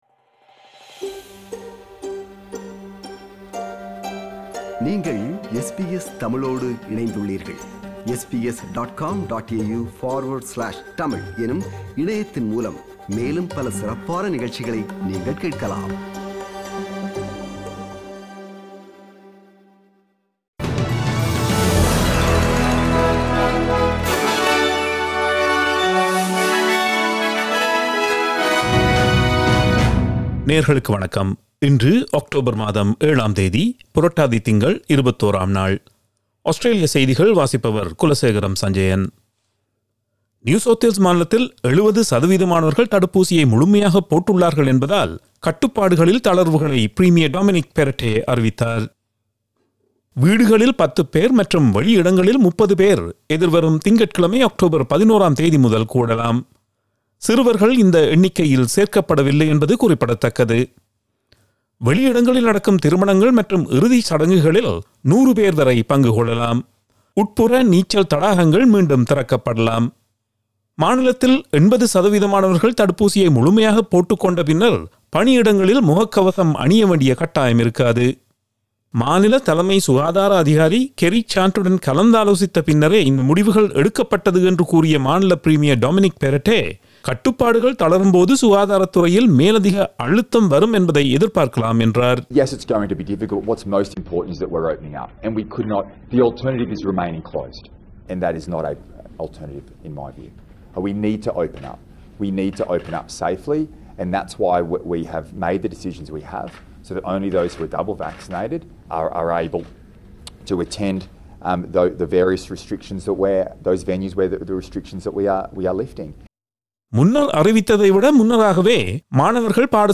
Australian news bulletin for Thursday 07 October 2021.